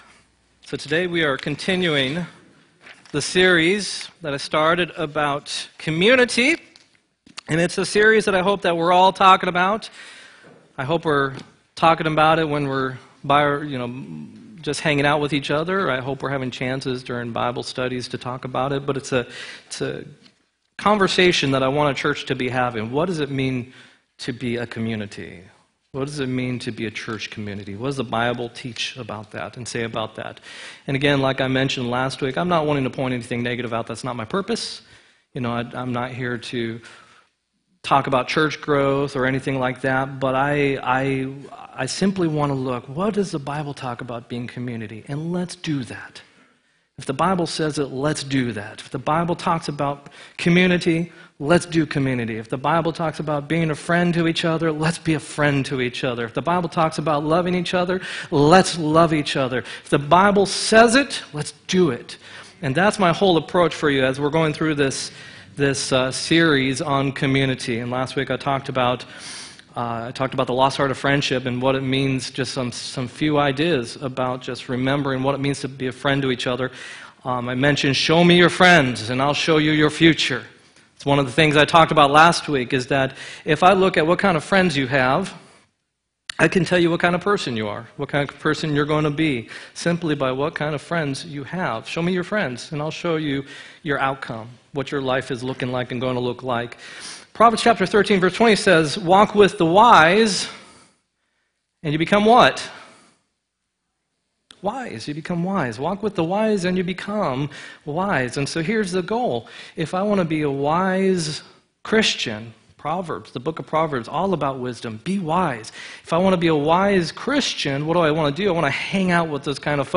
1-13-18 sermon
1-13-18-sermon.m4a